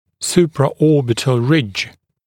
[ˌsuprə’ɔːbɪtl rɪʤ][ˌсупрэ’о:битл ридж]надбровная дуга